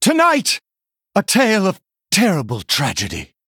VO_KAR_114_Male_Human_Play_05.ogg